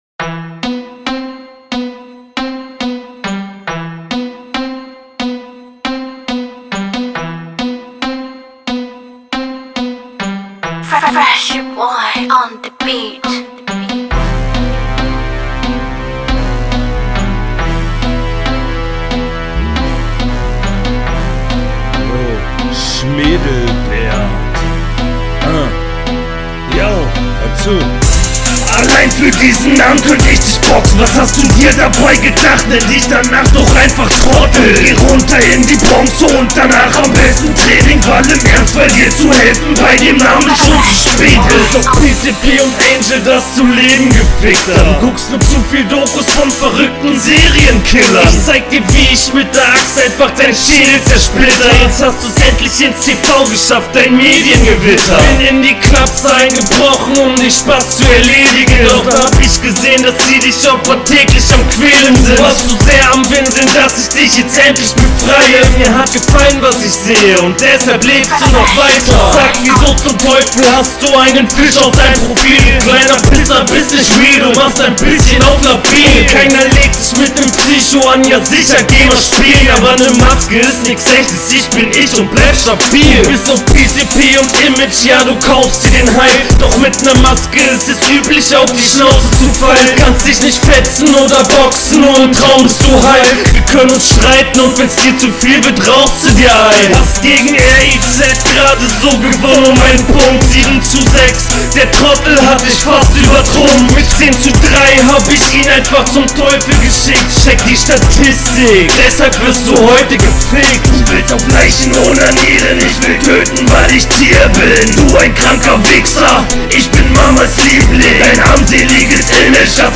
Die Stimmlage war nicht wegen dem Beat?
Auf dem Beat kommst du eindeutig besser. Aber das Soundbild ist seeehr.. mäßig :/ Punchlines …